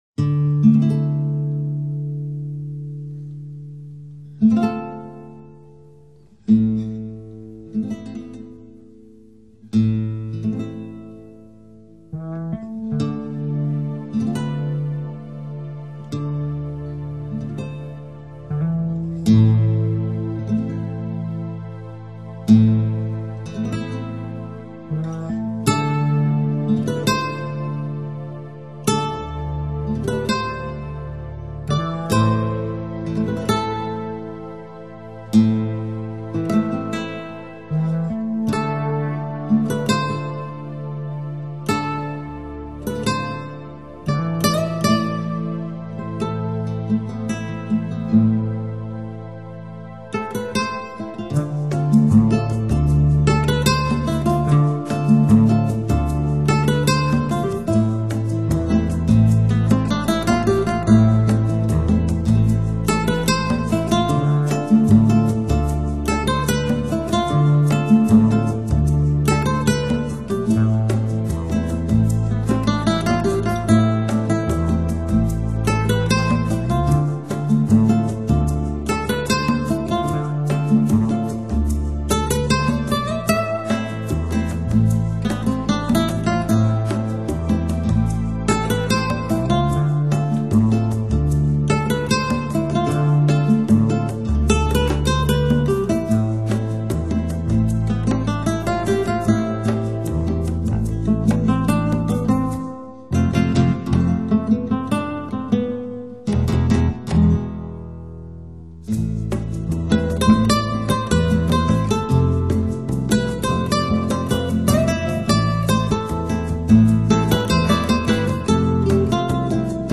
音乐类别：精神元素